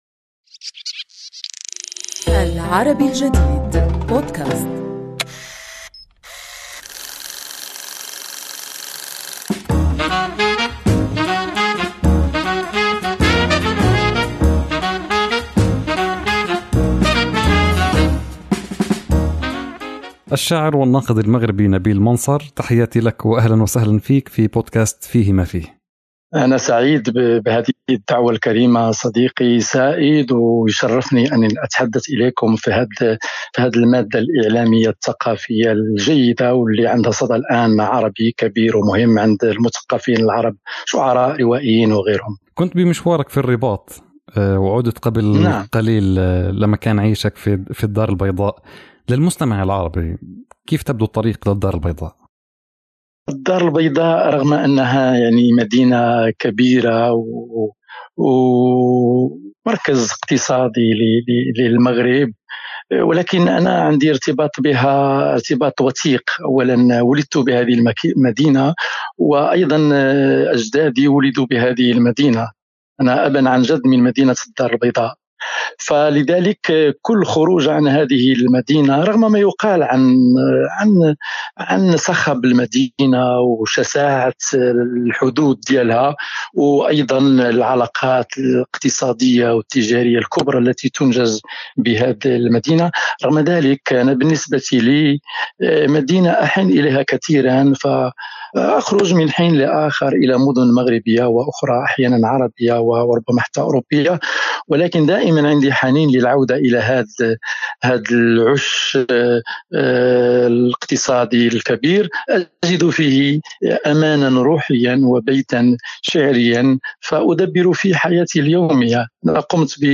نحاور خلالها الشاعر والناقد المغربي